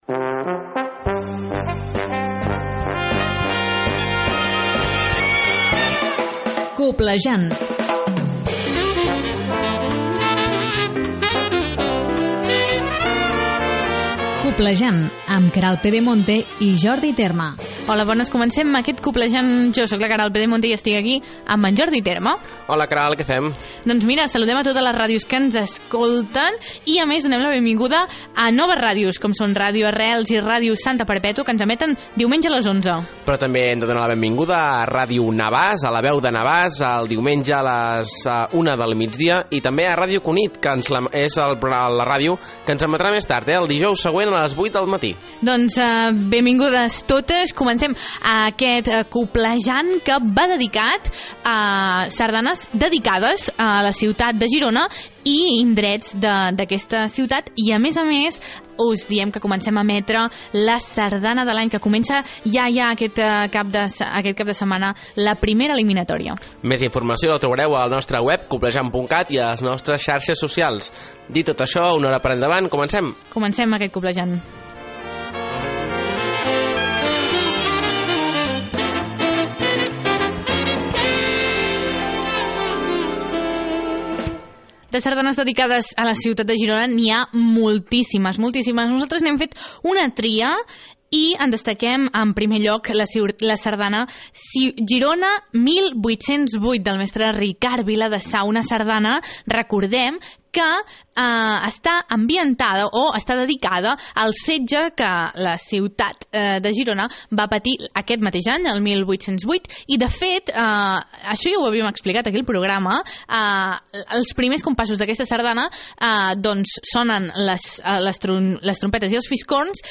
Aquest programa va dedicat a Girona amb sardanes compostes per retre homenatge a la seva ciutat i als seus indrets. També encetem nova secció que ve directament de la Confederació Sardanista de Catalunya: La Sardana de l’Any. Escoltarem un curt i un llarg de les tres sardanes que formen la primera eliminatòria.